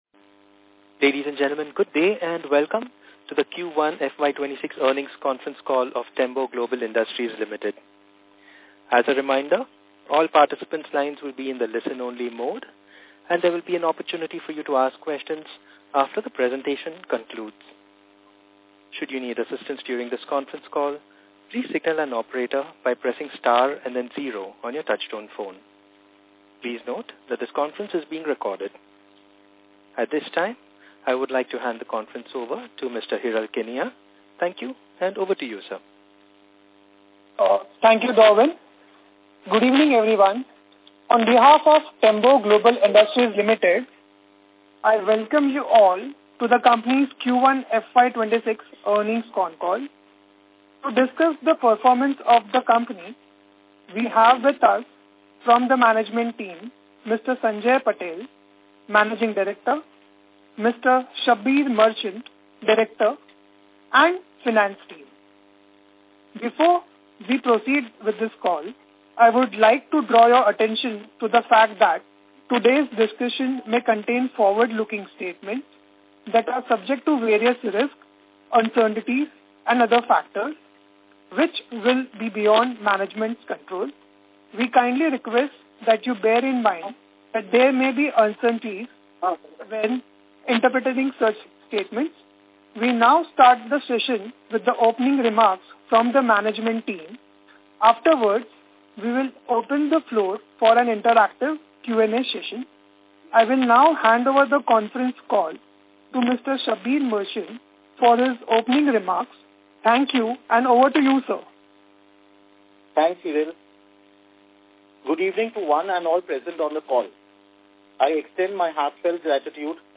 Concalls
Investor-Concall.mp3